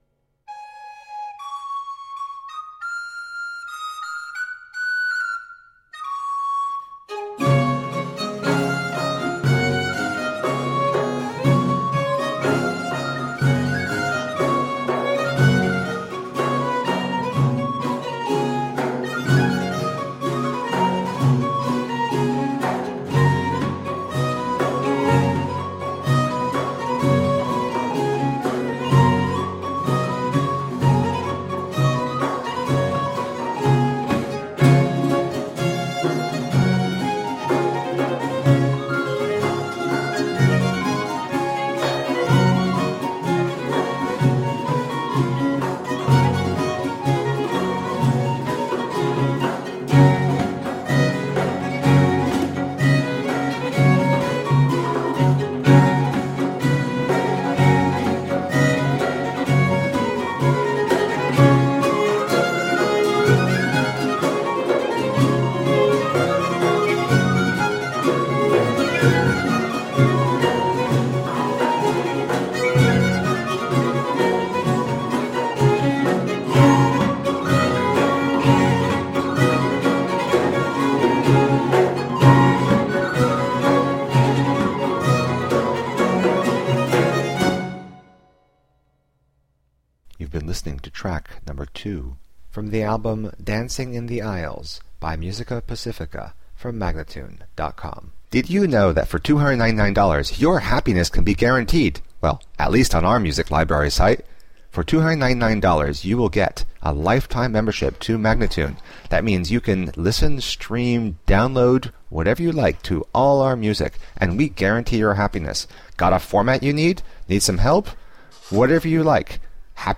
recorders and whistle
baroque violins
harpsichord
percussion